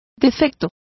Complete with pronunciation of the translation of defect.